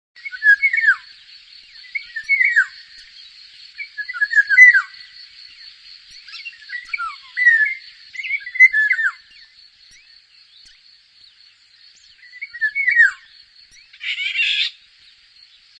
Pirol
Bei Störung oder Erregung ist ein heiseres "wiächt" oder "chräi" zu hören. Fühlt der Pirol sich angegriffen, ein hohes "gickgickgick" ("djick-jick").Das großflächige Zurückdrängen lichter Wälder mit hohen Altholzbeständen (z. B. Auwälder) sowie von Streuobstwiesen hat den Pirol bei uns zu einer recht seltenen Erscheinung werden lassen.
pirol.mp3